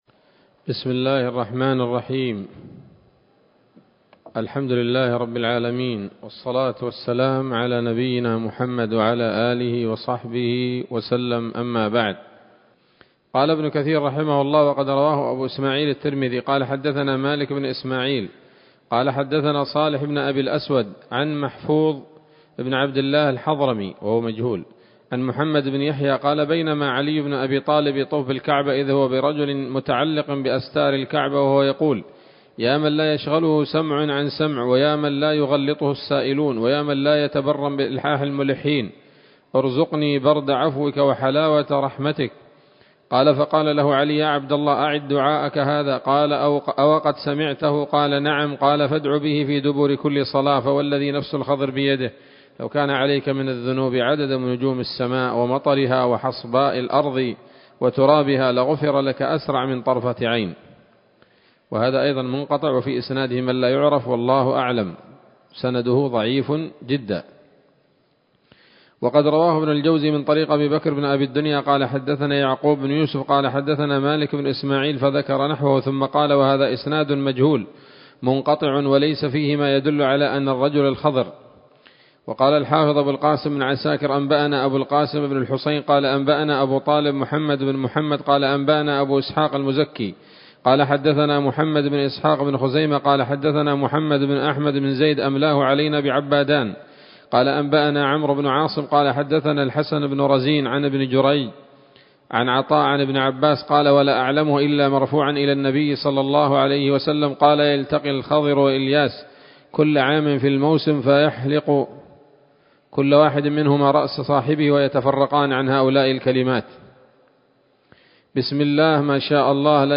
‌‌الدرس الثاني عشر بعد المائة من قصص الأنبياء لابن كثير رحمه الله تعالى